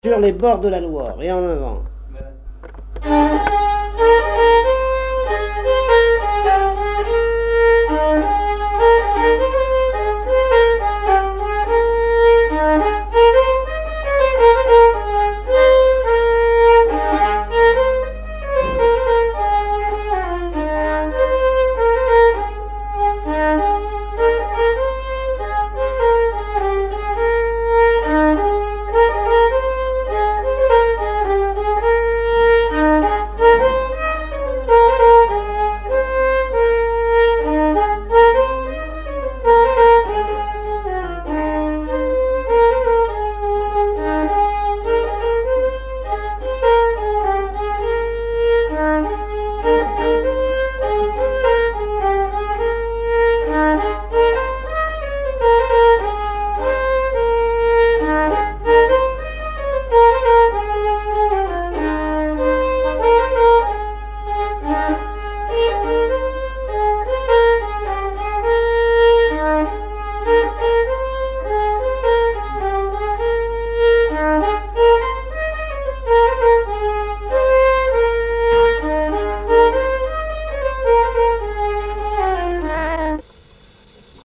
gestuel : à marcher
Genre laisse
Pièce musicale inédite